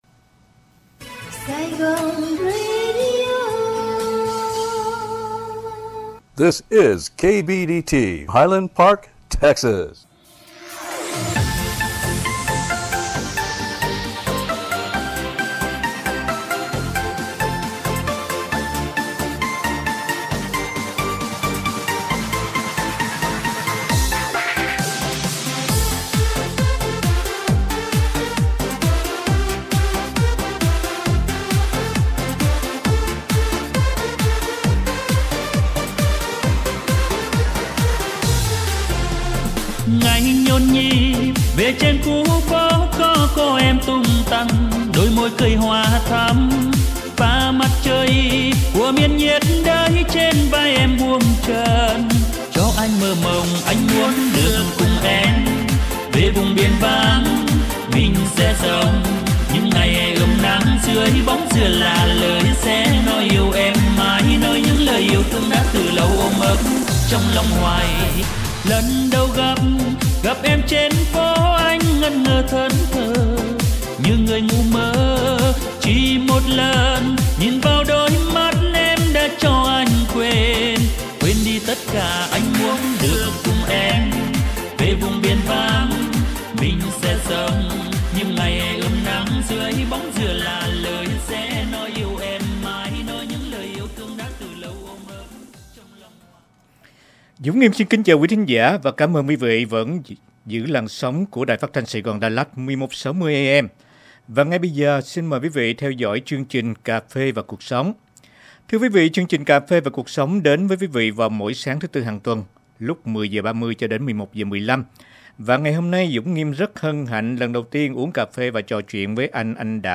Cà phê & Cuộc sống: Talk show